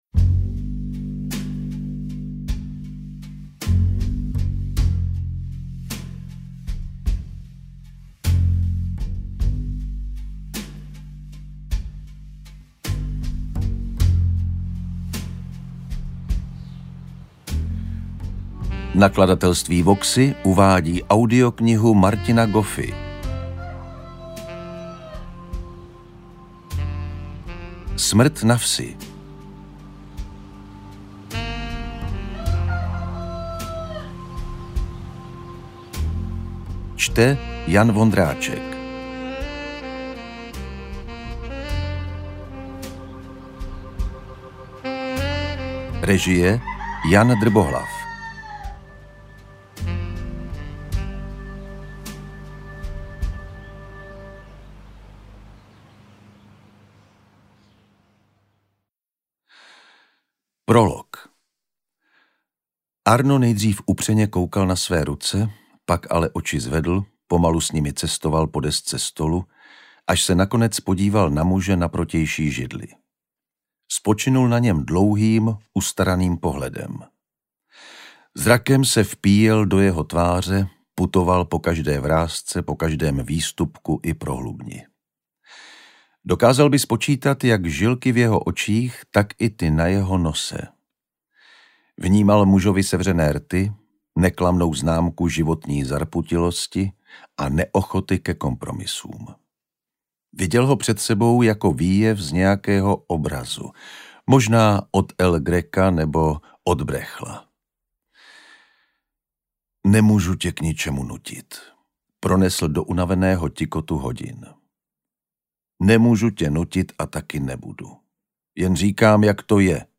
Interpret:  Jan Vondráček